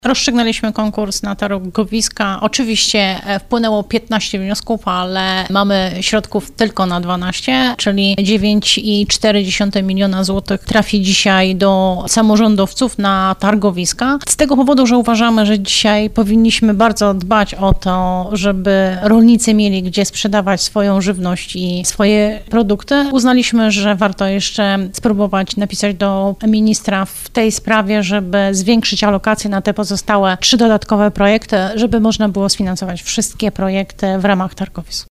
Członek zarządu województwa Janina Ewa Orzełowska: